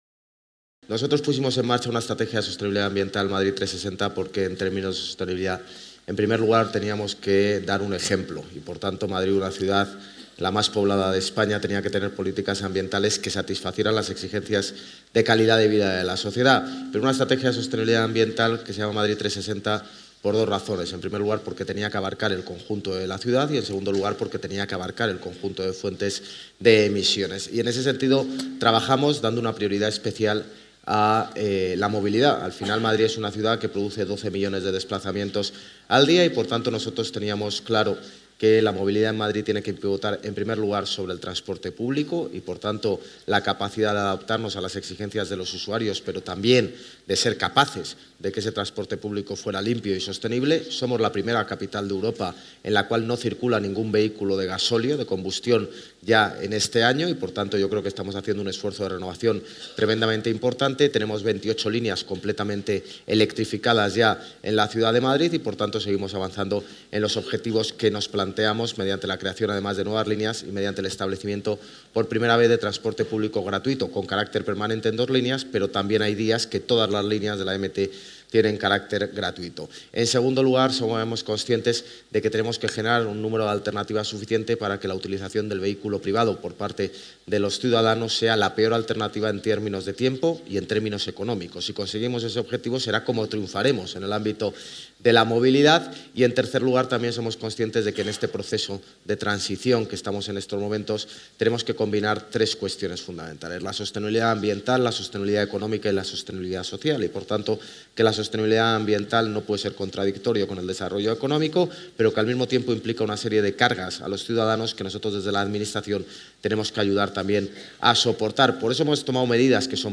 Durante su intervención en la mesa redonda ‘La movilidad cero emisiones en nuestras ciudades’ enmarcada en la IV edición del Foro ANFAC
Nueva ventana:José Luis Martínez-Almeida, alcalde de Madrid